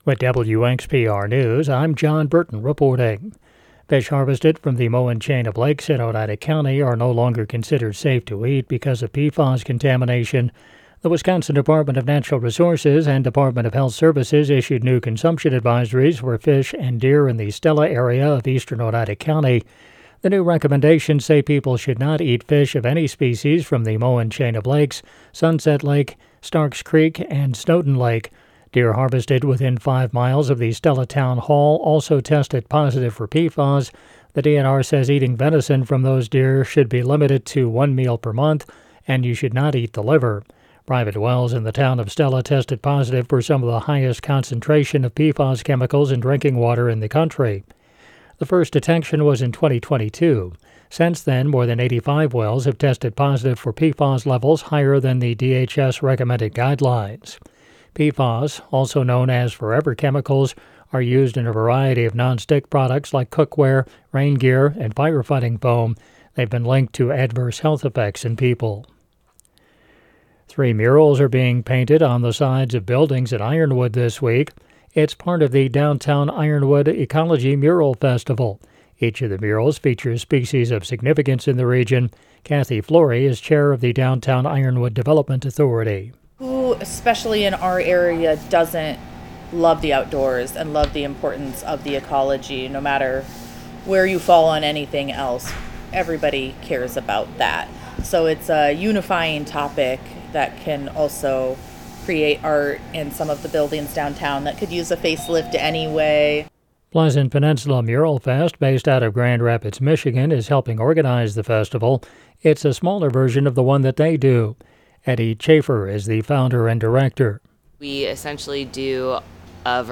The Extra is WXPR’s daily news podcast. Get the news you need to stay informed about your local community – all in six minutes or less.